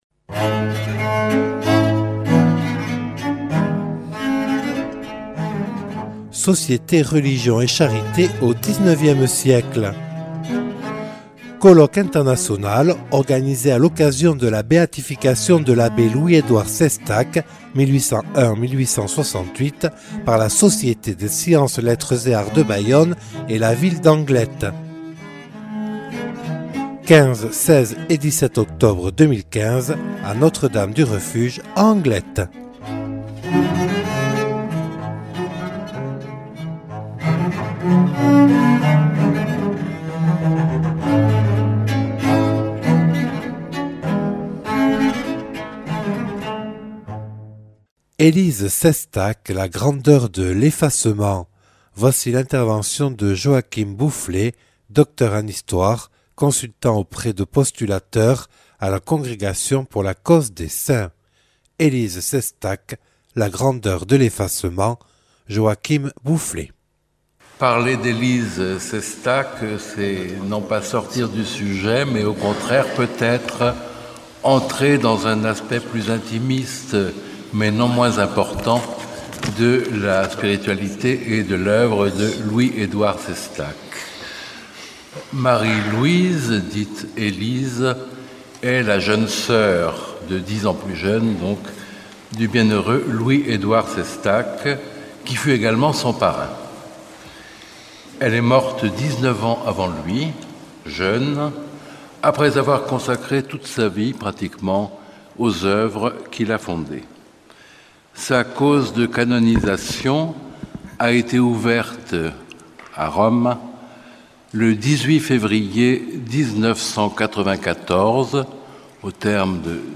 (Enregistré le 15/10/2015 à Notre Dame du Refuge à Anglet).